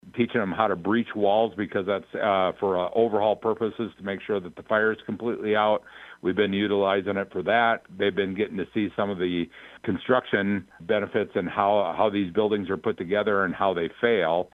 HE SAYS THERE’S OTHER PRACTICAL TRAINING THAT’S BEEN UNDERWAY: